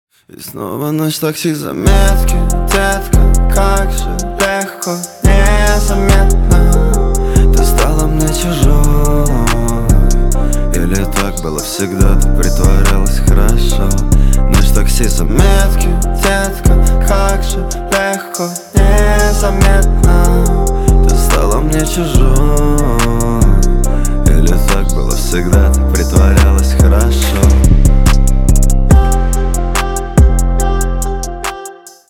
Поп Музыка
грустные
тихие